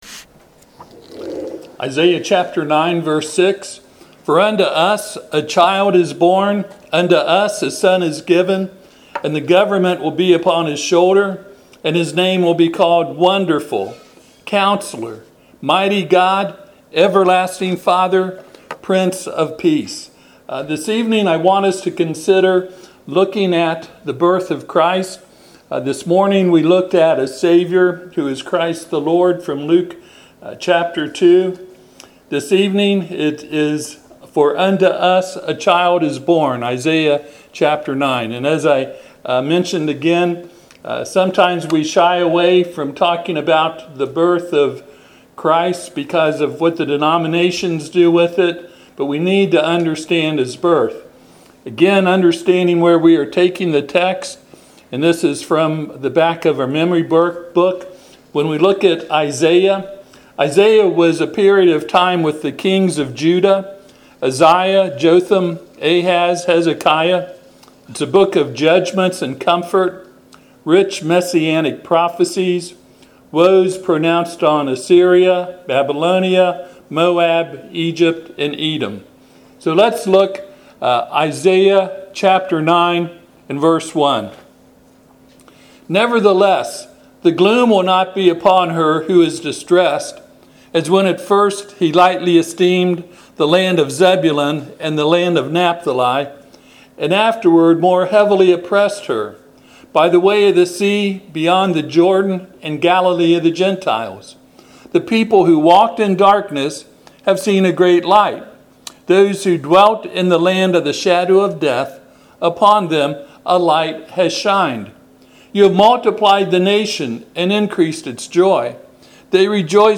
Passage: Isaiah 9:1-7 Service Type: Sunday PM